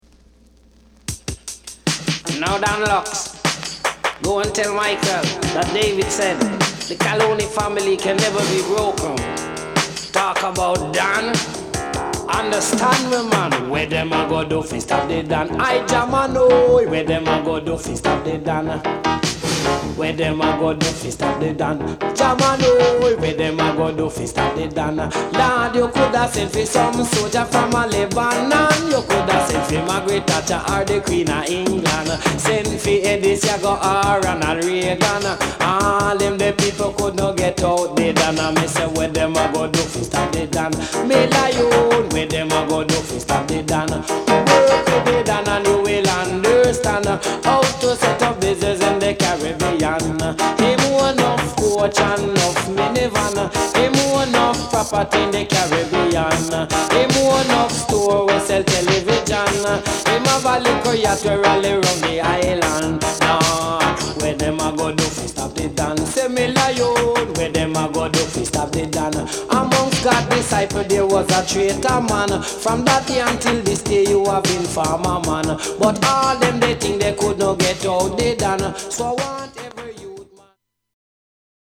REGGAE / DJ
デジタル・ダンスホール初期ですが